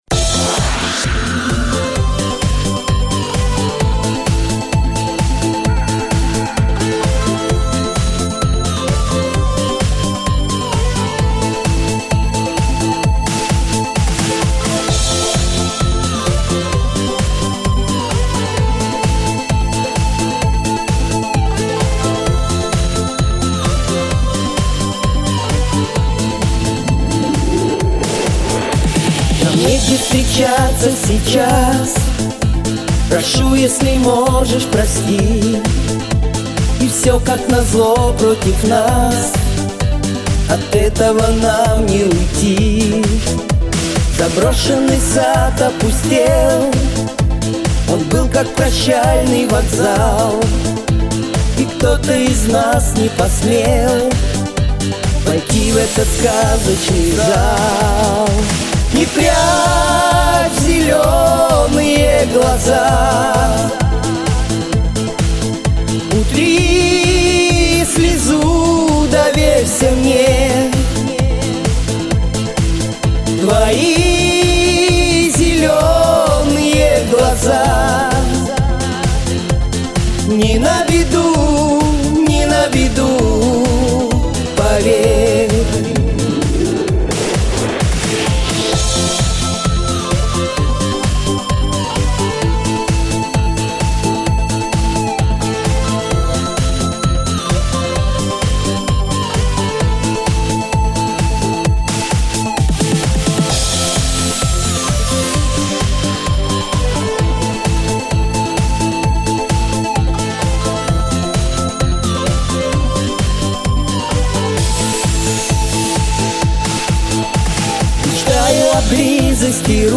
тут дуэт!